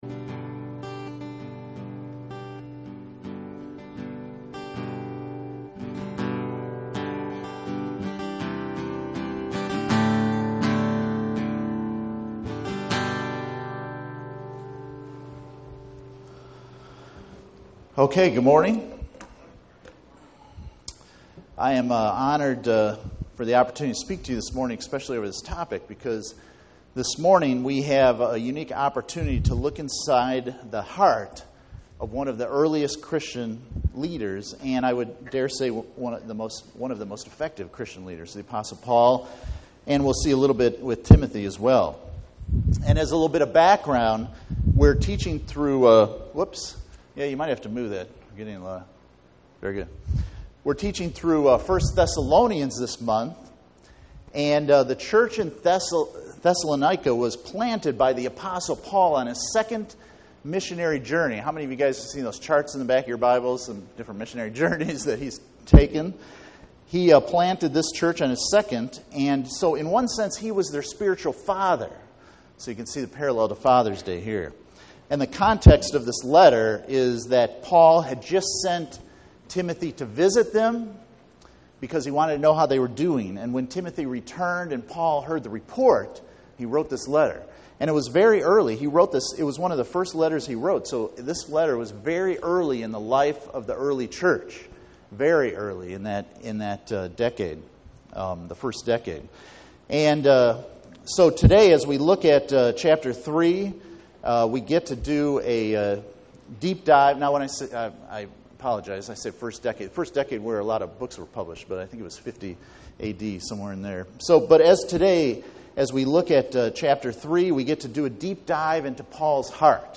Passage: 1 Thessalonians 3:1-13 Service Type: Sunday Morning